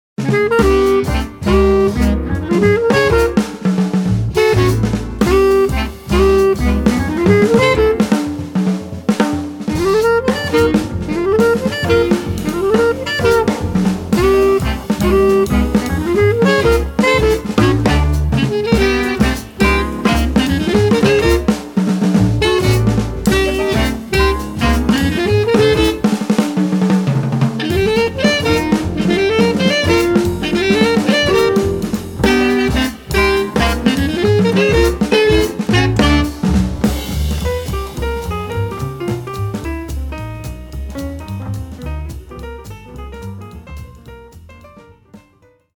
Bass Clarinet
Piano
Double-Bass
Drums
at Skyline Production, South Orange, NJ